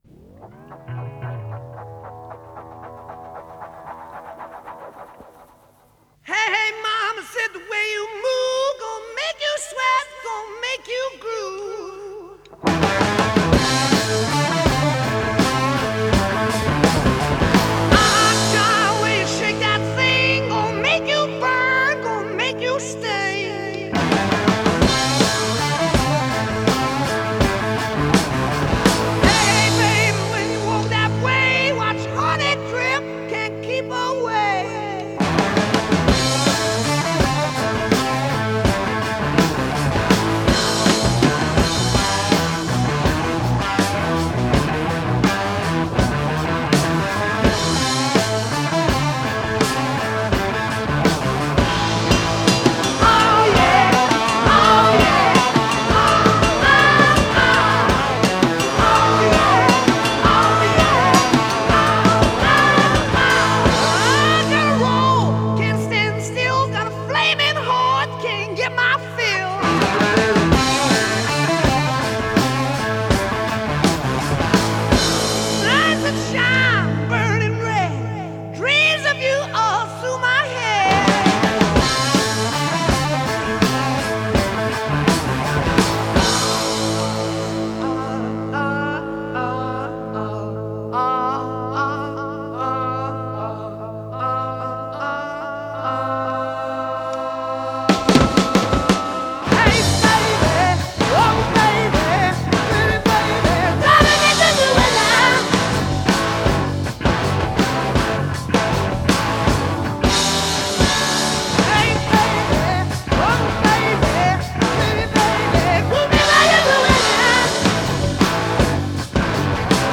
Hard Rock, Blues Rock